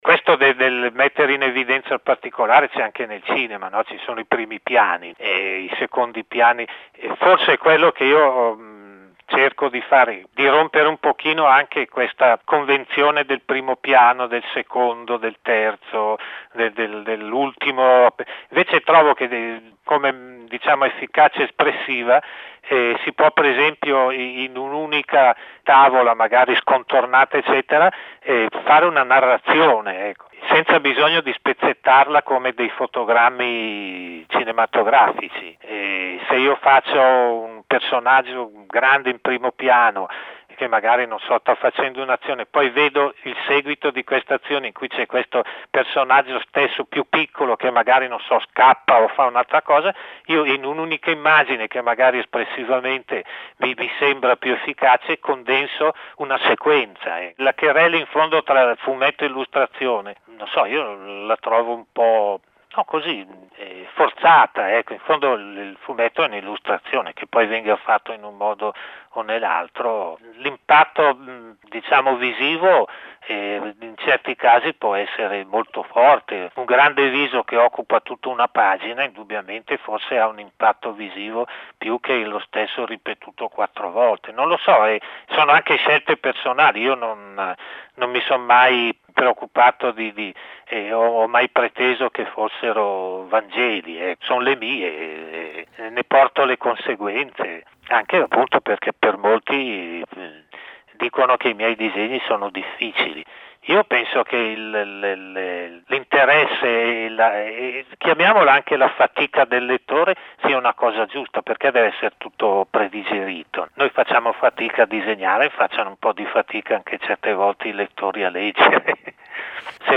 Bilbolbul dedica una grande retrospettiva all’opera di uno dei maestri del fumetto italiano. Ascolta Sergio Toppi ai microfoni di Città del Capo – Radio Metropolitana.